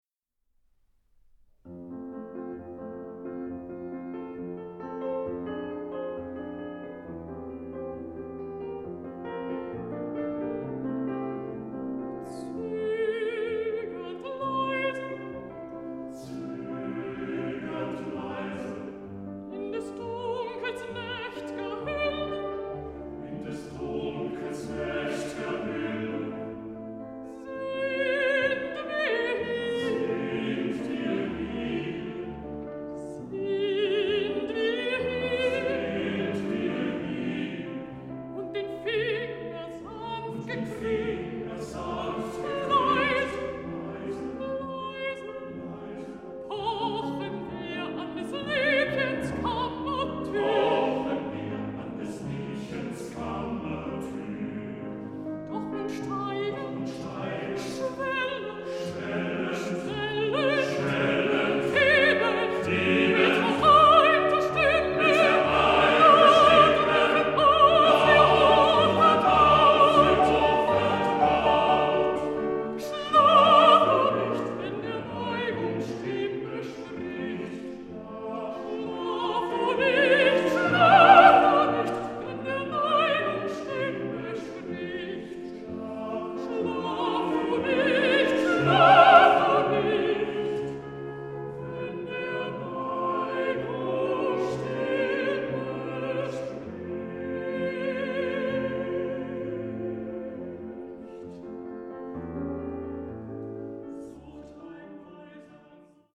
Gesänge für Männerstimmen und Klavier
Alt
Klavier